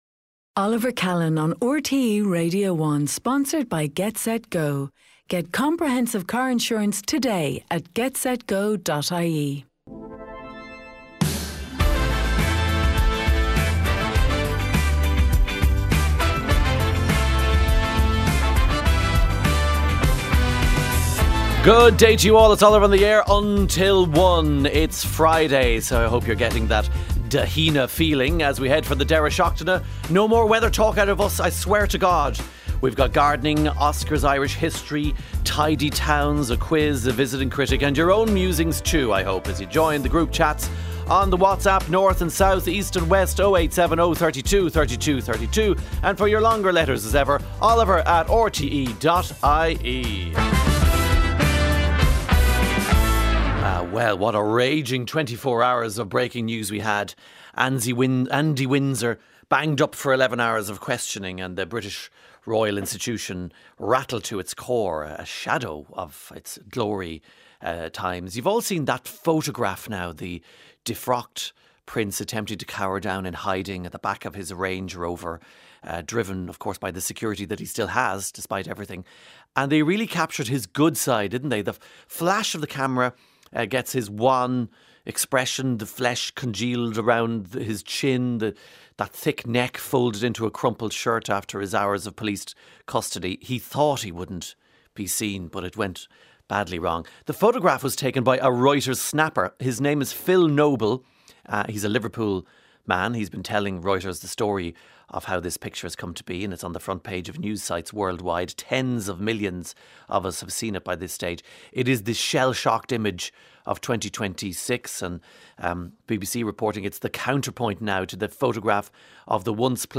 monologue on a Friday.